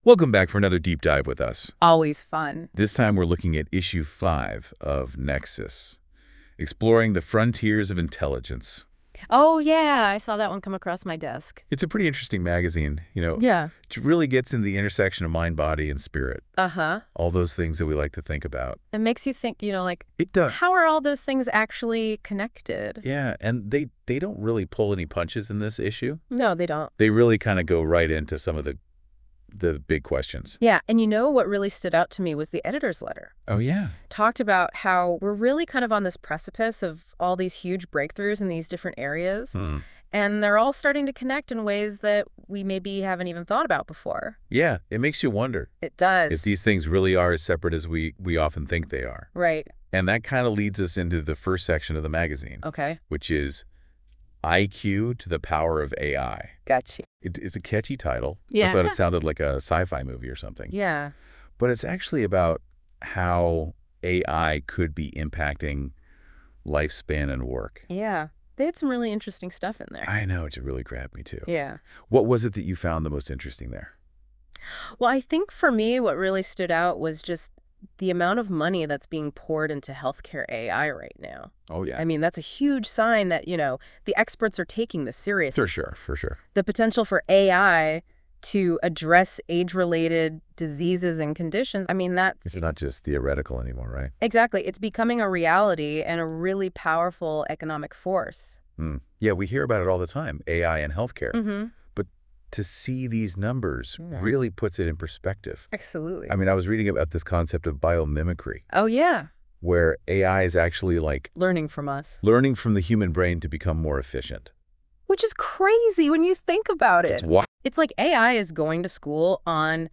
Nexus Deep Dive is an AI-generated conversation in podcast style where the hosts talk about the content of each issue of Nexus: Exploring the Frontiers of Intelligence .